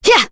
Maria-Vox_Attack2.wav